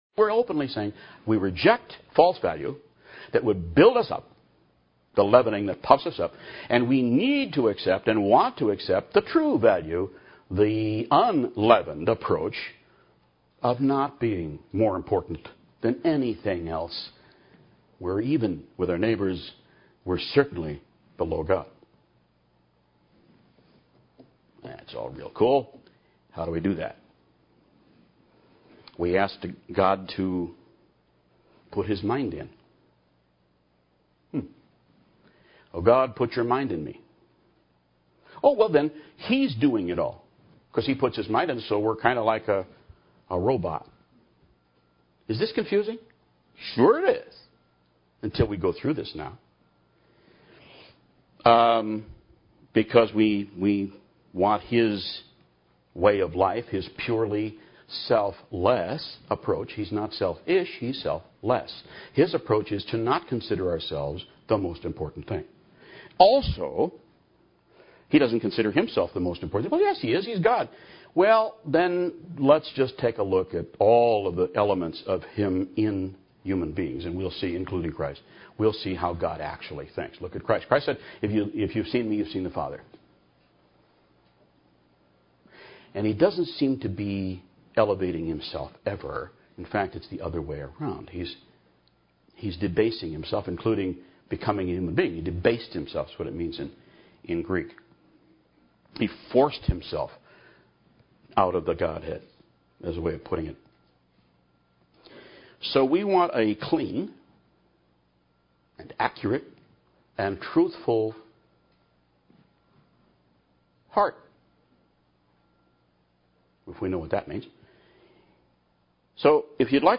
Sermon “After God’s Own Heart” Last DUB AM Madison 4/13/12